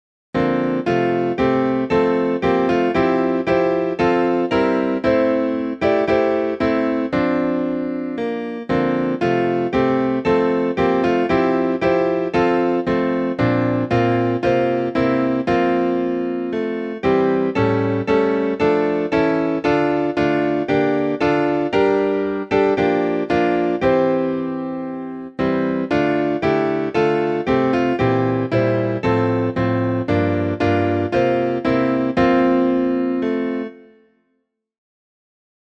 Voicing/Instrumentation: SATB
Praise